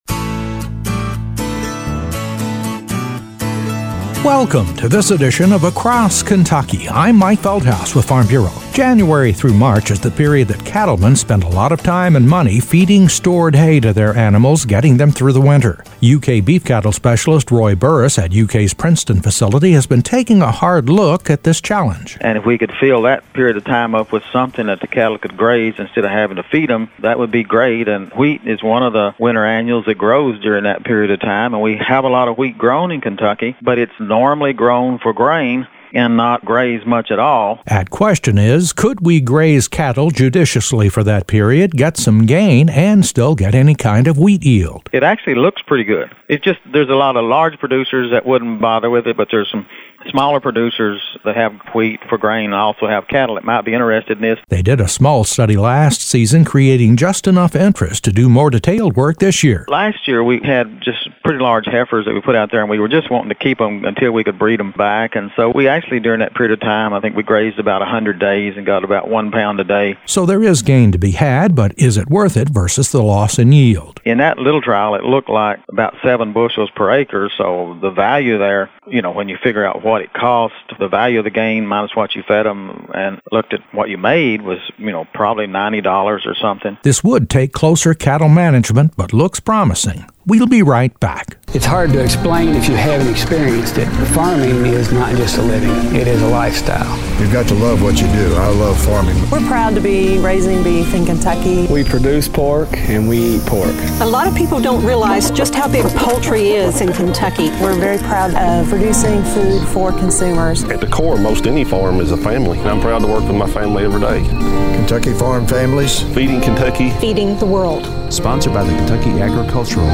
A report on initial success with a research project at UK’s Princeton facility examining the potential for grazing cattle for short periods on winter wheat.